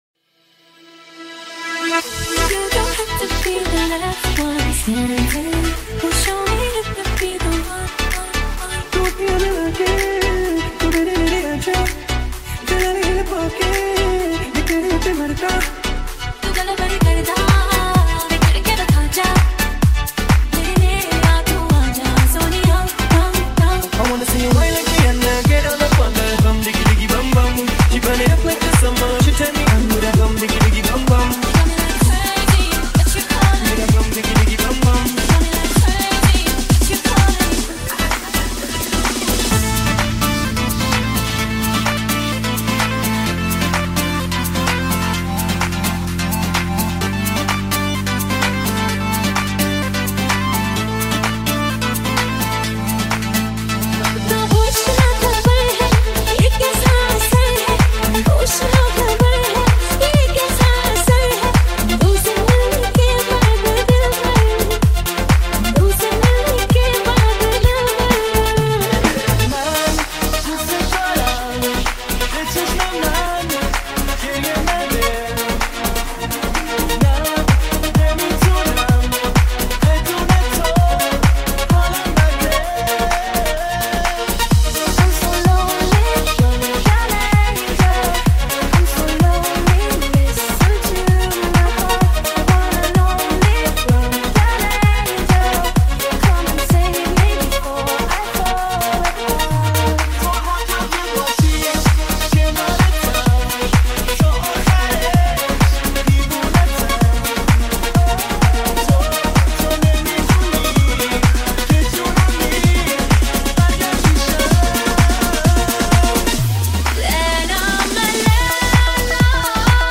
High quality Sri Lankan remix MP3 (5.7).